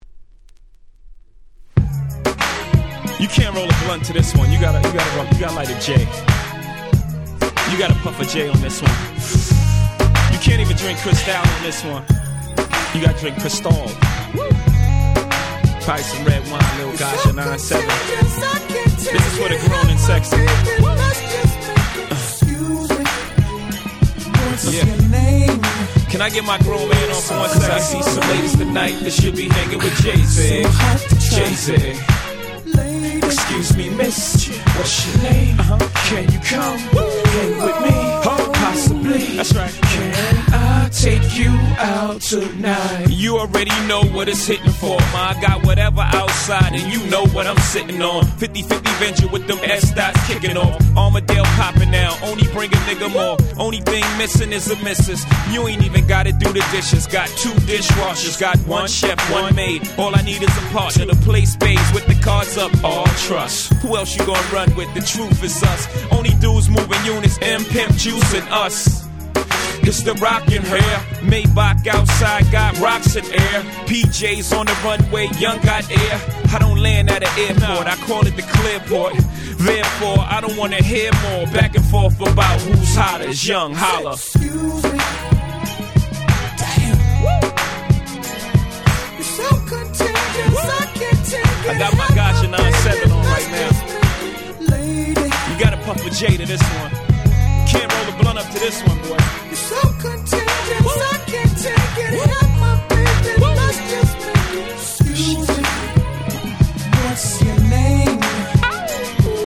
03' Smash Hit Hip Hop !!